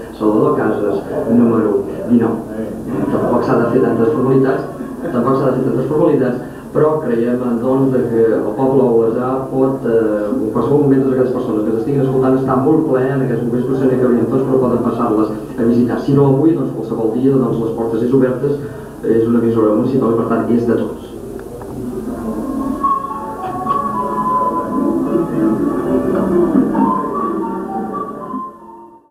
Programa especial el dia de la inauguració dels nous estudis. Inivitació a la població a que els visiti.